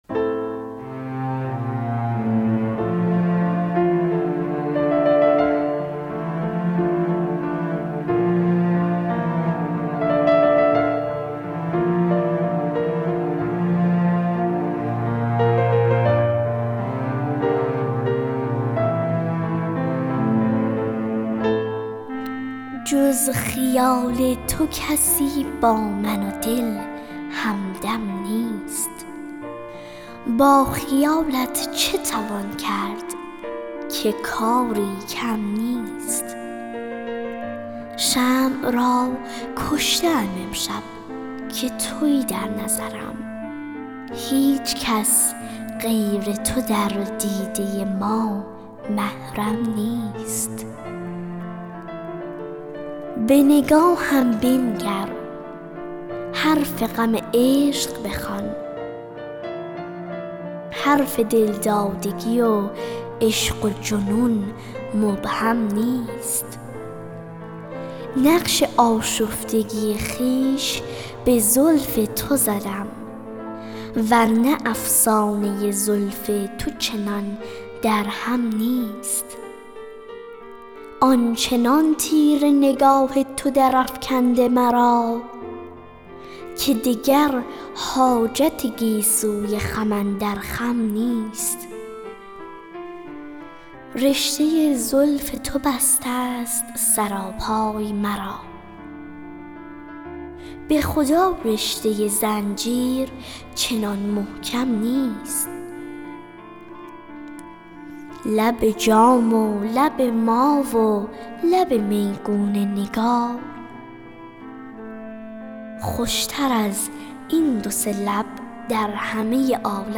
دکلمه اشعار همراه با موسیقی